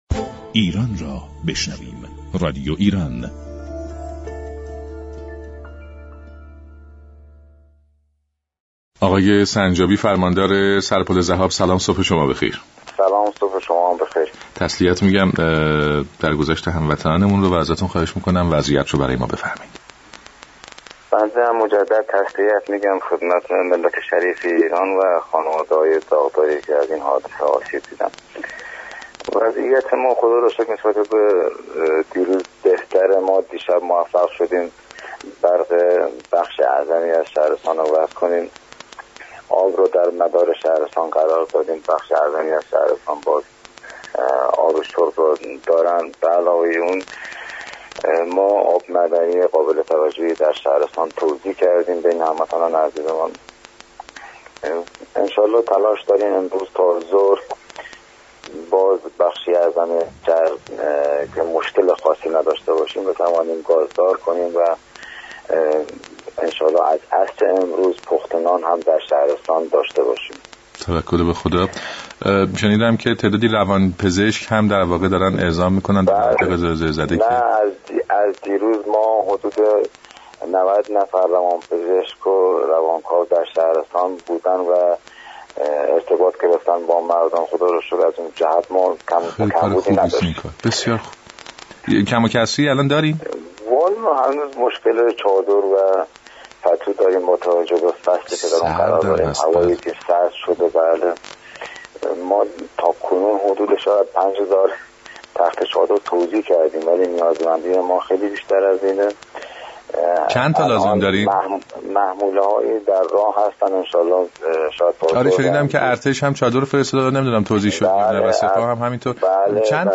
فرماندار سر پل ذهاب، در گفت و گو با برنامه «سلام ایران» گفت: با آنكه تاكنون حدود 5000 پتو و تخته چادر توزیع شده ولی به دلیل سردی و برودت هوا این مقدار هنوز كافی نیست و شهرستان در این زمینه به شدت نیازمند كمك رسانی است.